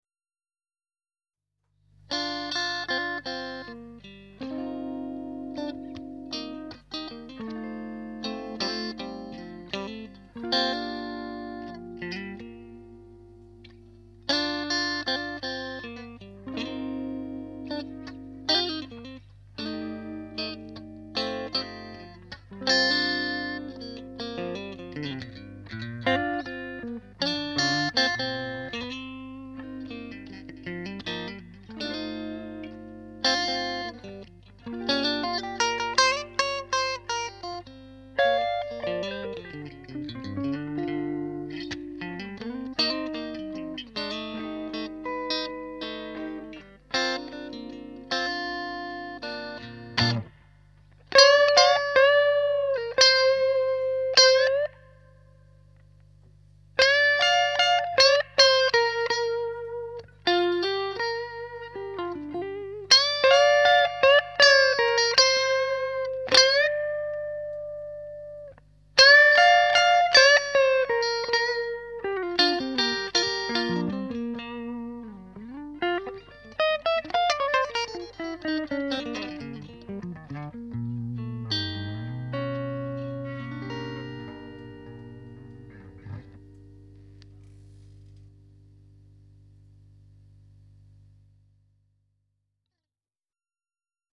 01:08 Avec juste deux prises, une à gauche et une à droite, sur une URL qui marche, c'est quand même plus pratique pour entendre ce LOREDO en sons clairs : http